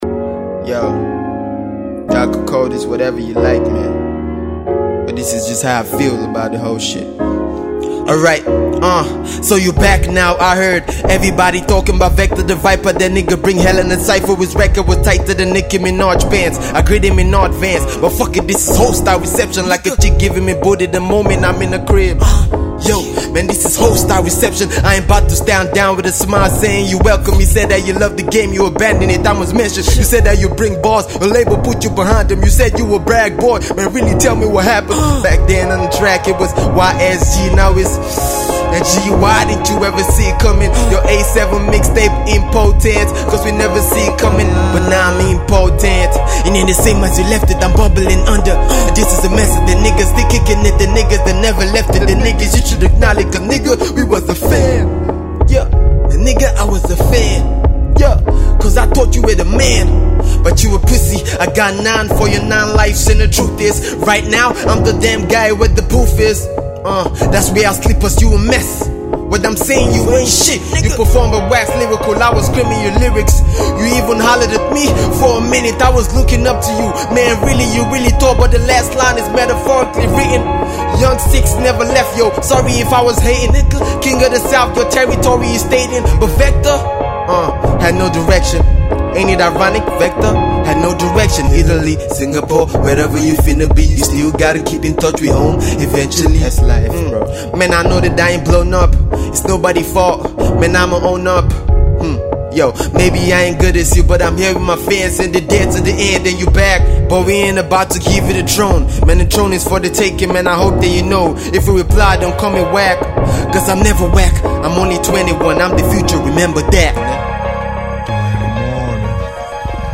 diss track